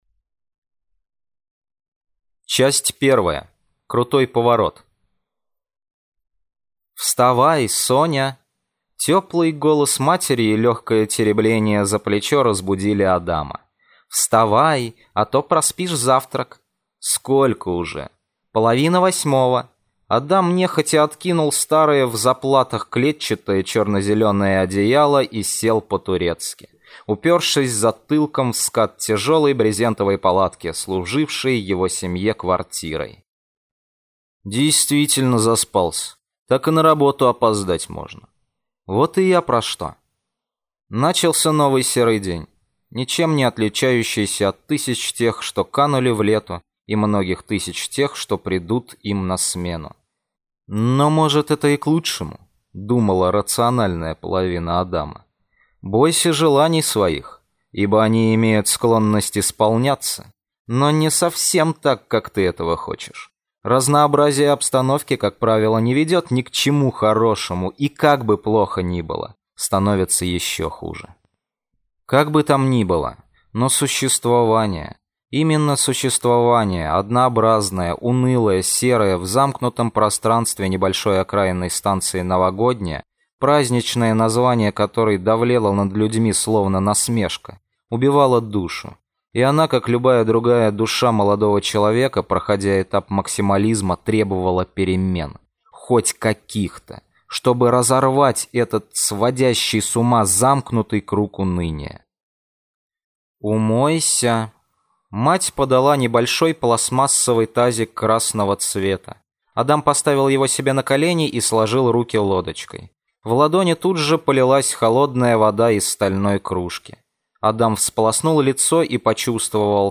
Аудиокнига Адам. Метро 2033.